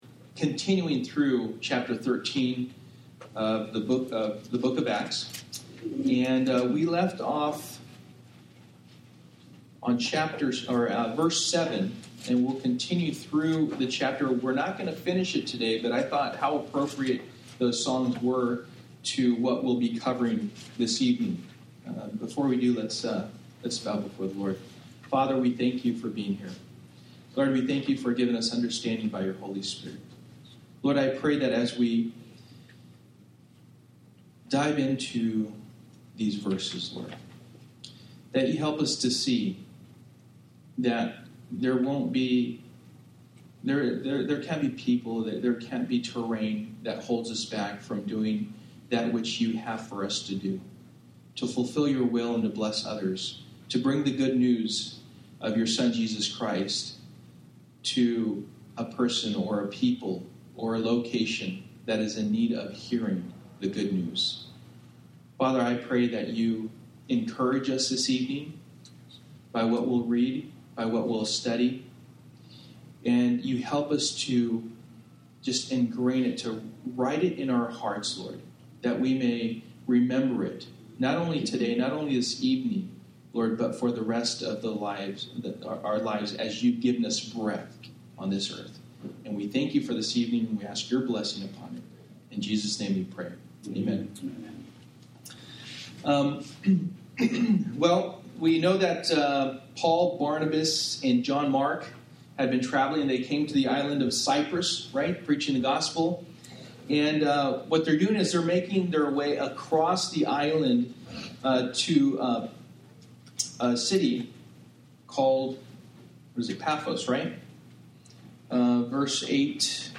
Service: Wednesday Night